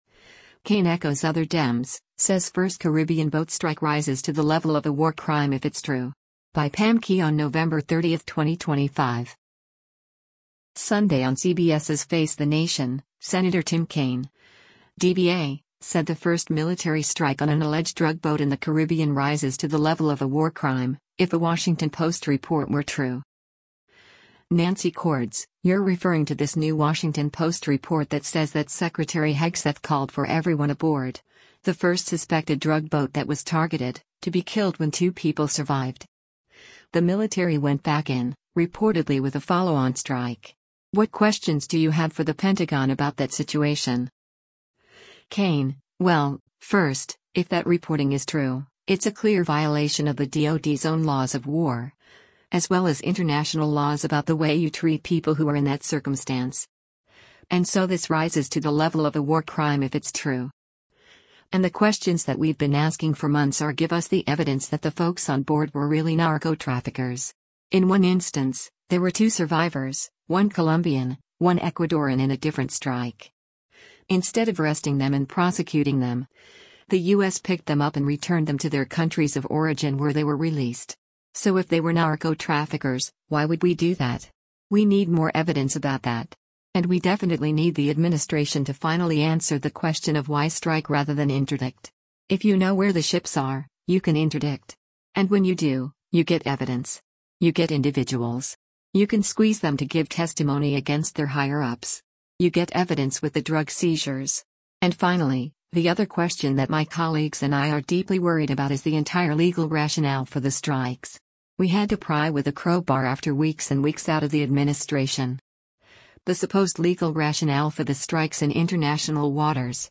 Sunday on CBS’s “Face the Nation,” Sen. Tim Kaine (D-VA) said the first military strike on an alleged drug boat in the Caribbean “rises to the level of a war crime,” if a Washington Post report were true.